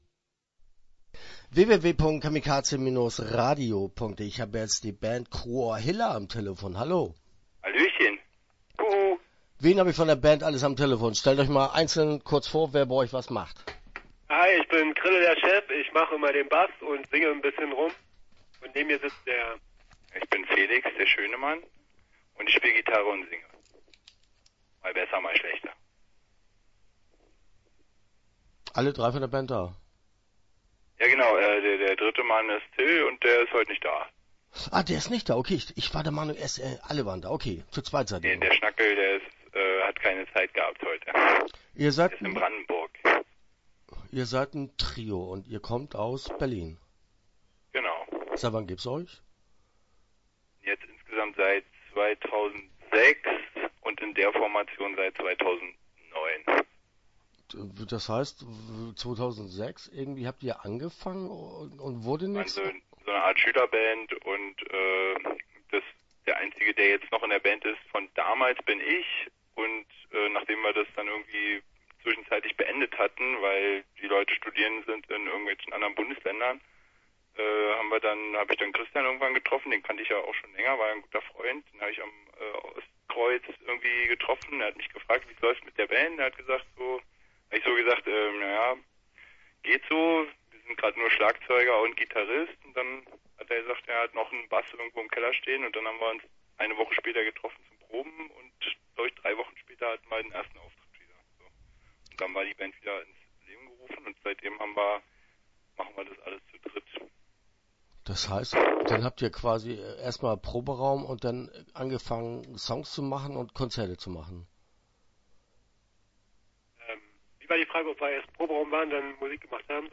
Start » Interviews » Cruor Hilla